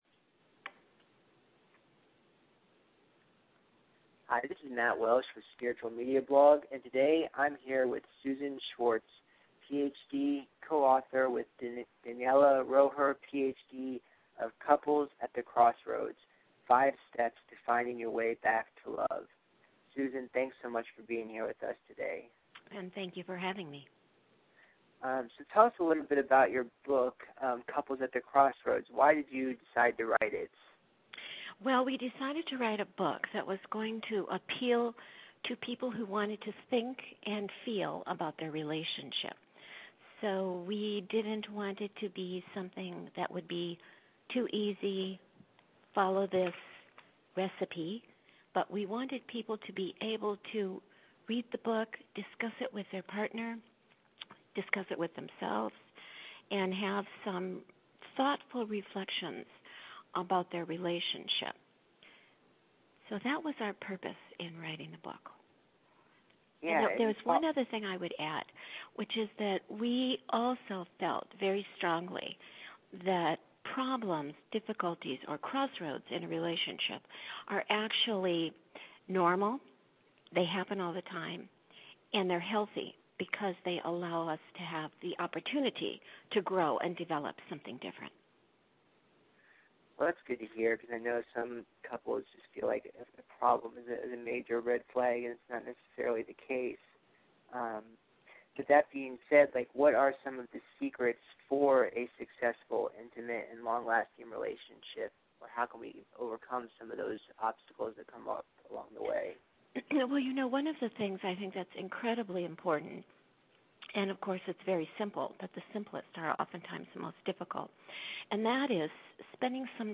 Spiritual-Media-Blog-interview.mp3